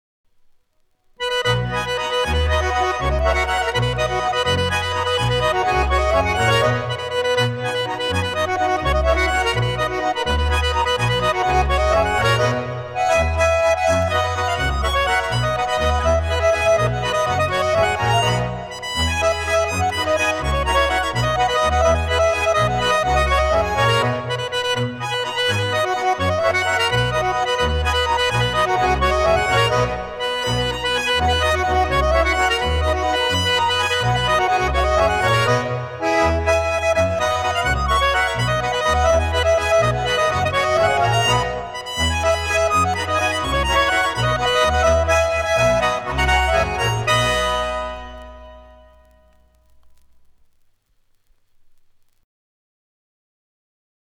Ländler